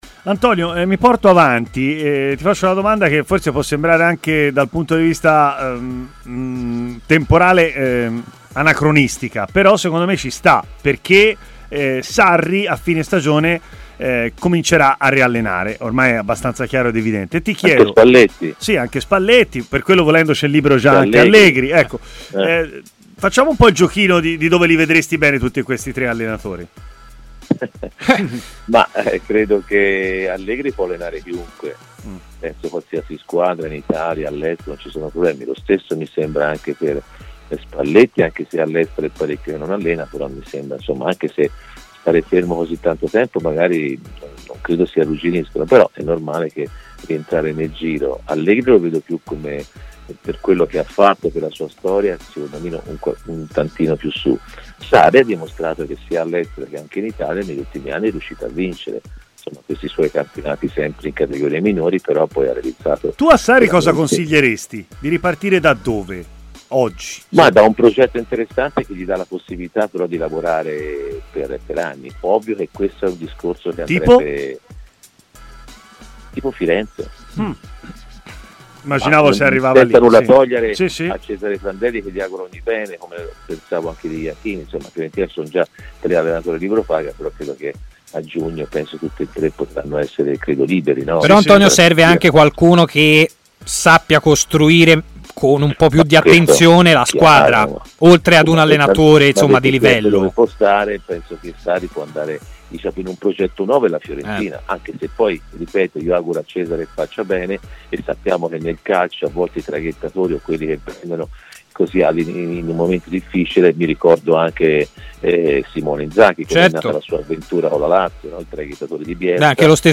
L'ex centrocampista Antonio Di Gennaro ha parlato in diretta a Stadio Aperto, trasmissione di TMW Radio: "L'Inter deve vendere per poi comprare, mentre penso che Milan e Juventus debbano fare acquisti, soprattutto questi ultimi che a fine dicembre in un giorno hanno perso 6 punti.